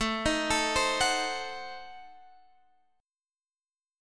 Adim7 chord